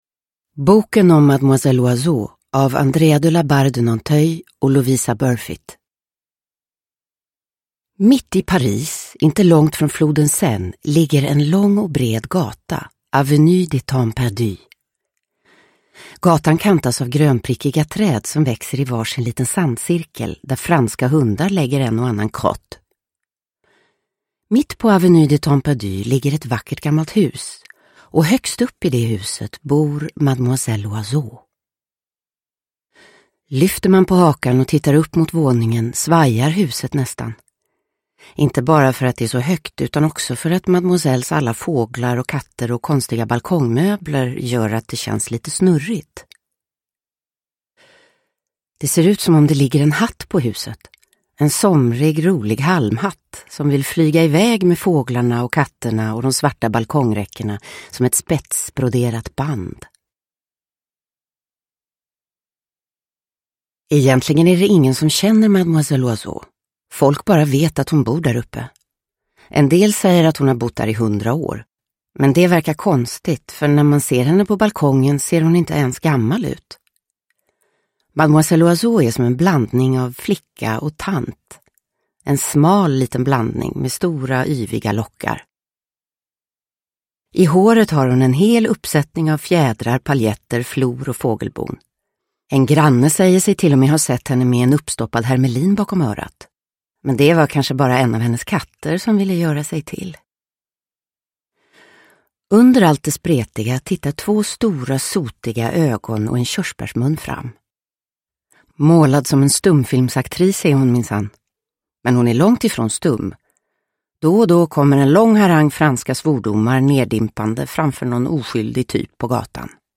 Boken om Mademoiselle Oiseau – Ljudbok – Laddas ner
Uppläsare: Livia Millhagen